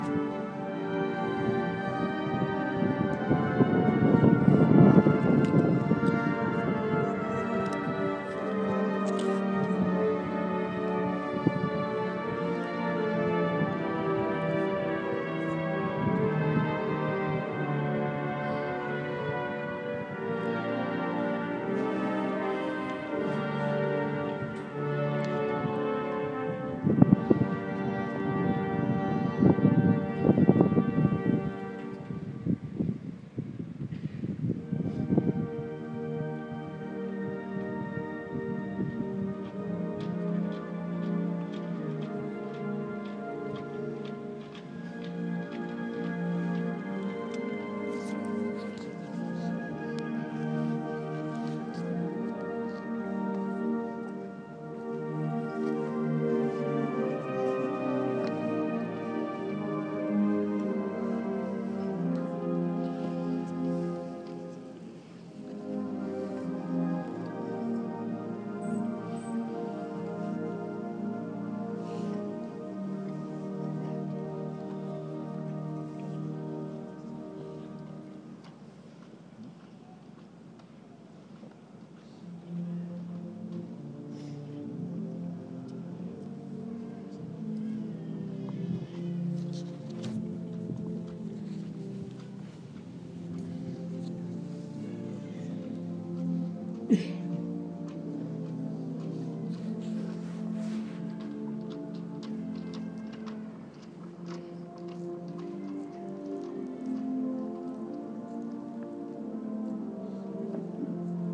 Moving music from Remembrance Day at Whitehall